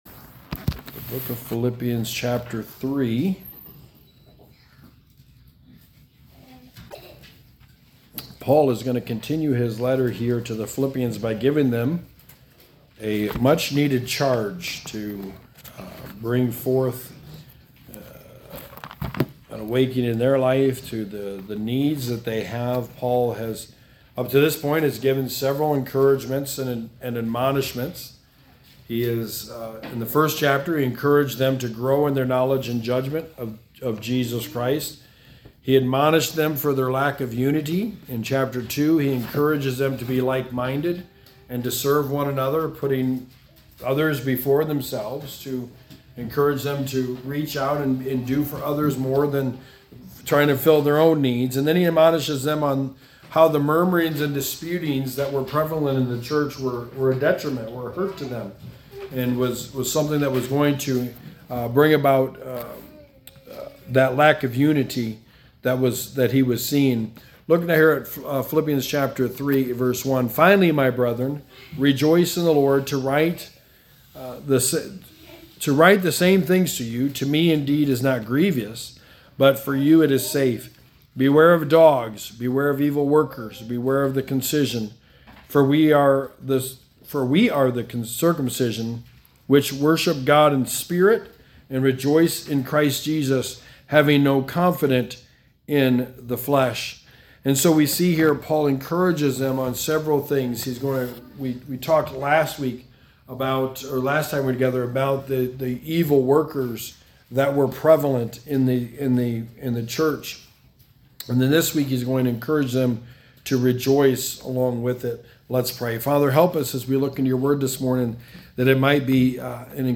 Sermon 17: The Book of Philippians: Rejoice in the Lord